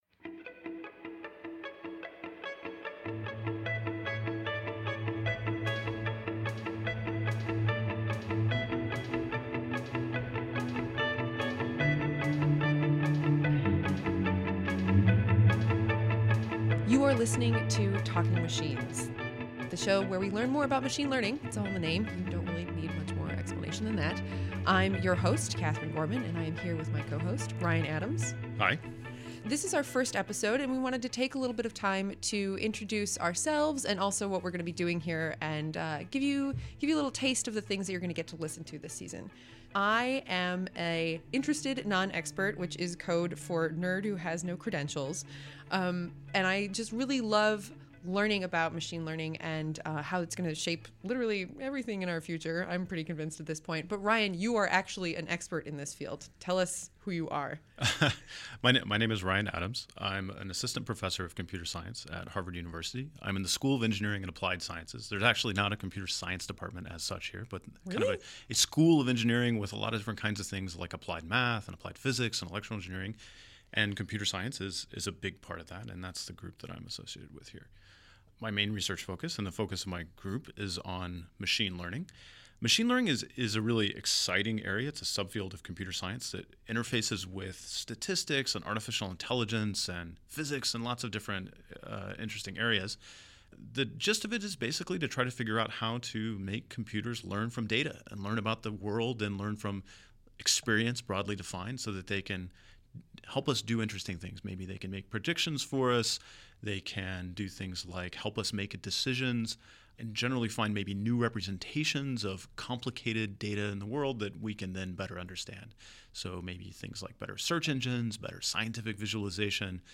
Today we hear some short clips on big issues, we'll get technical, but today is all about introductions.
Finally, we sit down with three pillars of the field Yann LeCun, Yoshua Bengio, and Geoff Hinton to hear about where the field has been and where it might be headed.